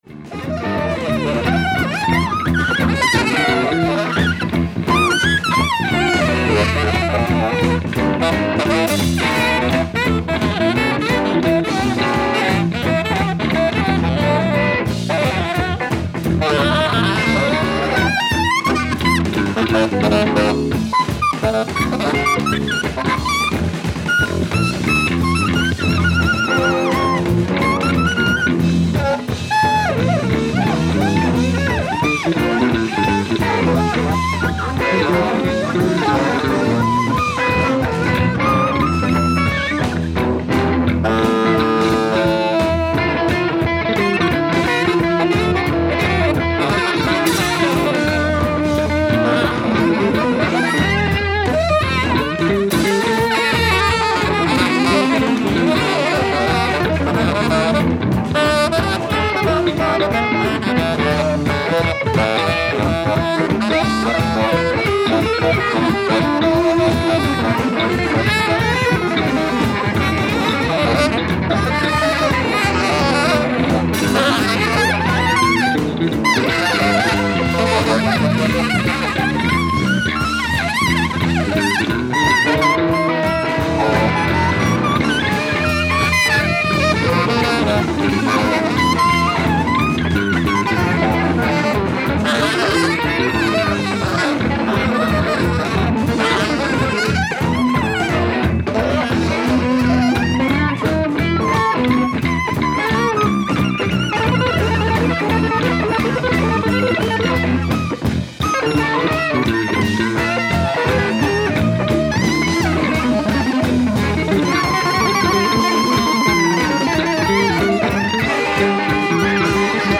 ライブ・メールス・ジャズ・フェスティバル、メールス、ドイツ
※試聴用に実際より音質を落としています。